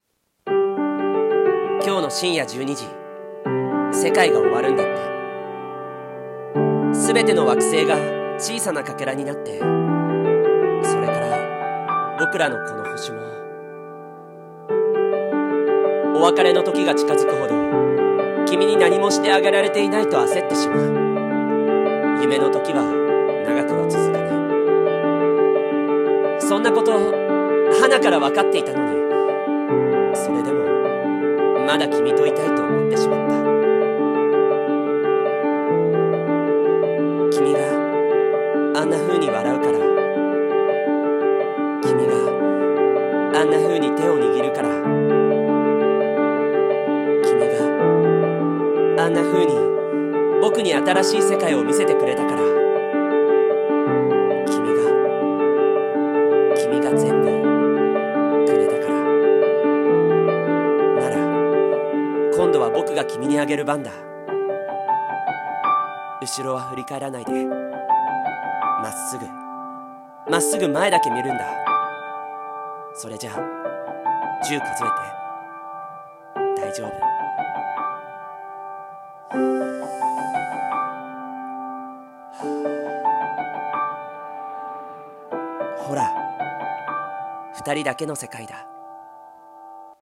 【1人声劇】世界最期の贈り物に、